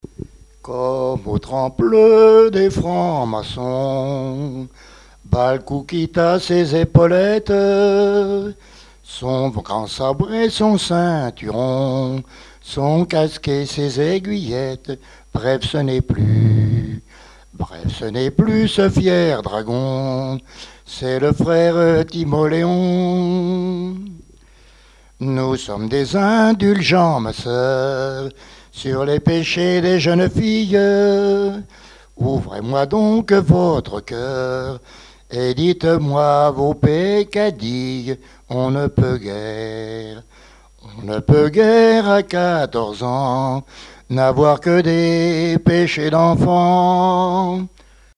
Genre strophique
interprétation de chansons traditionnelles et populaires
Pièce musicale inédite